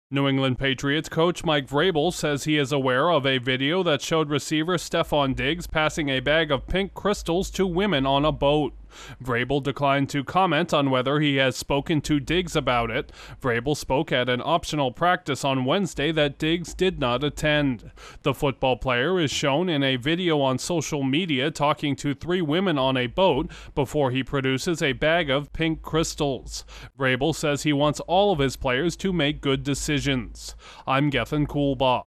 A former All-Pro wide receiver is in hot water after being recorded passing out an unknown substance. Correspondent